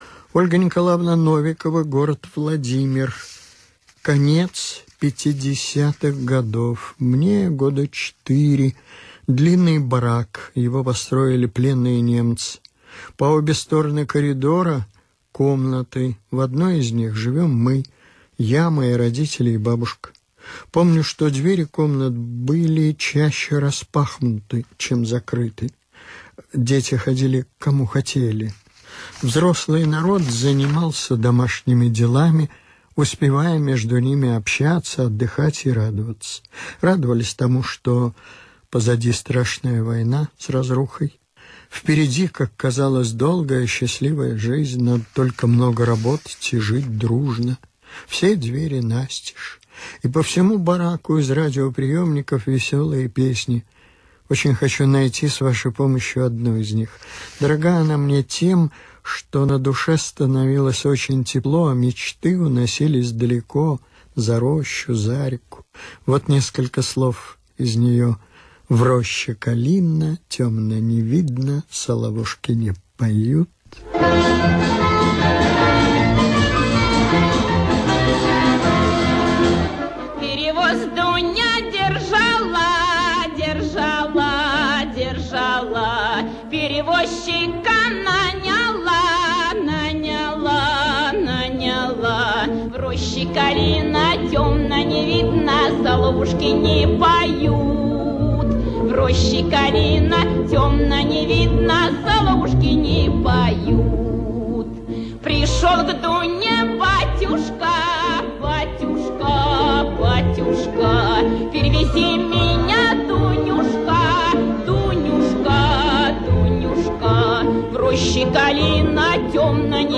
В передаче "Встреча с песней" прозвучала русская народная песня "Перевоз Дуня держала".